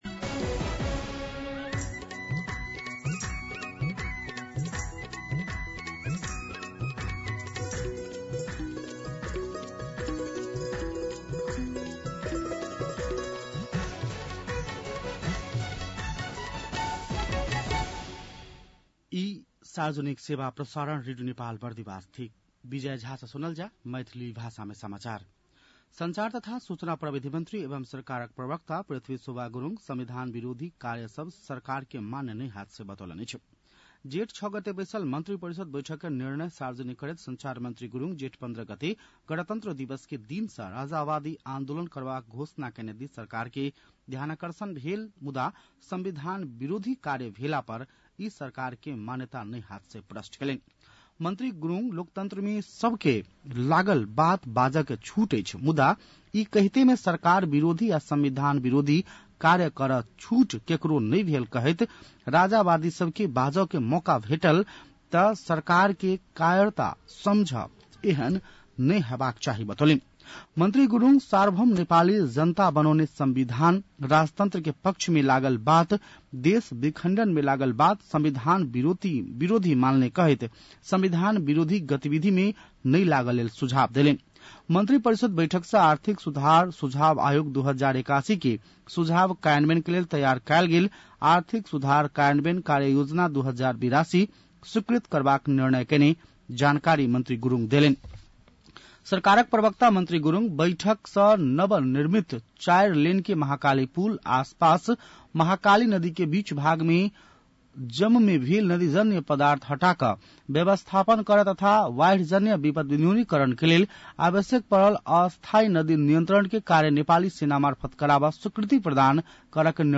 मैथिली भाषामा समाचार : ८ जेठ , २०८२
6.-pm-maithali-news-1-2.mp3